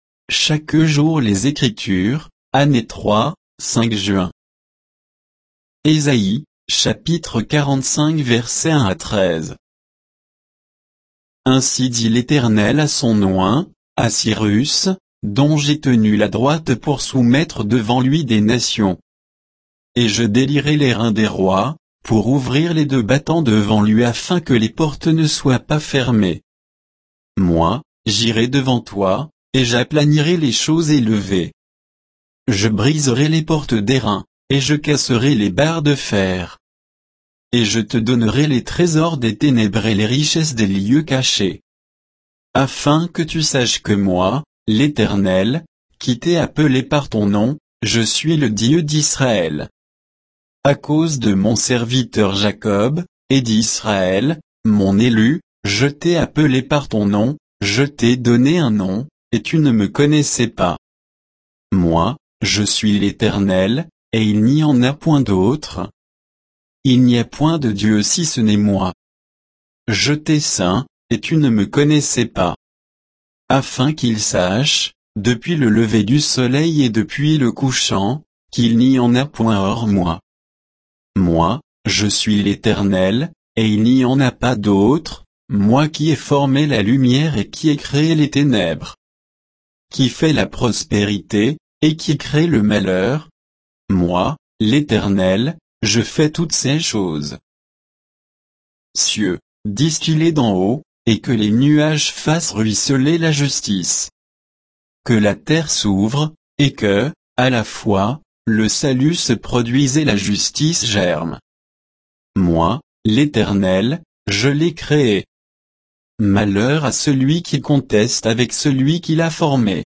Méditation quoditienne de Chaque jour les Écritures sur Ésaïe 45, 1 à 13